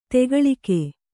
♪ tegaḷike